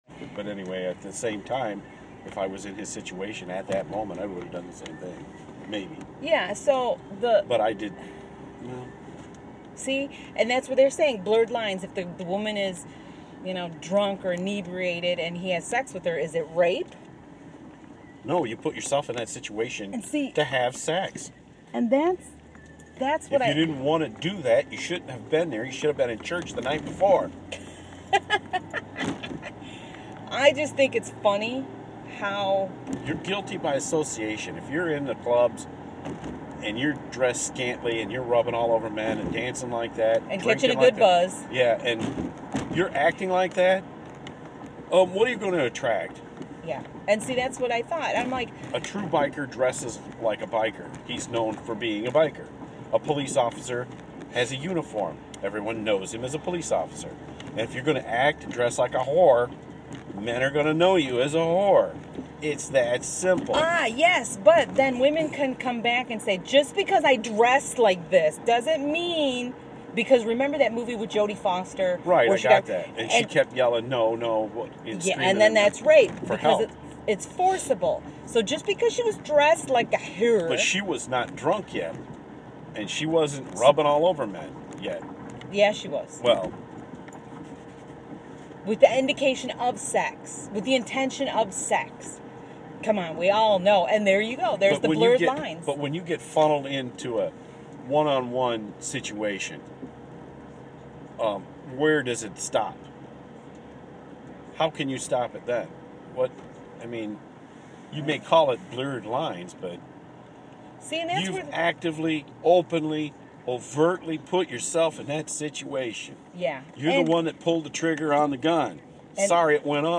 A Couple Discussing...